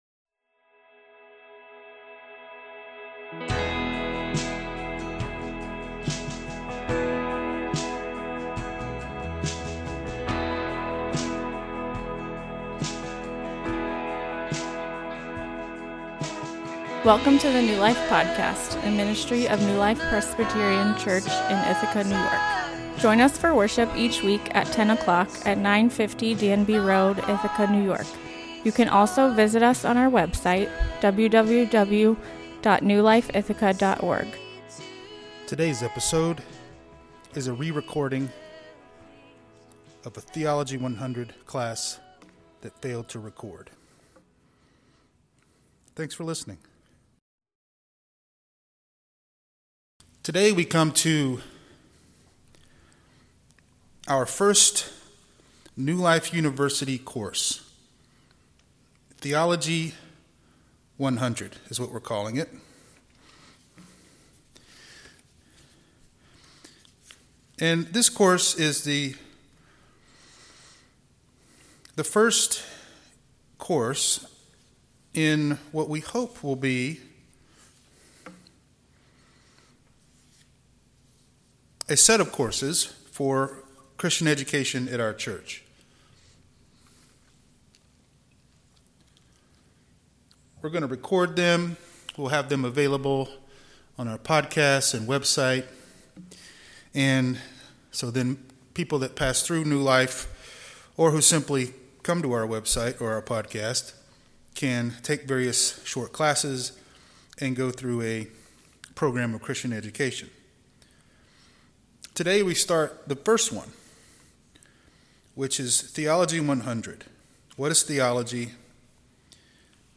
This is the first class in a five week Christian education class called Theo 100, an introduction to Christian Theology. In this episode we talk about what theology is, why it’s relevant to you, and what the sources for theology are.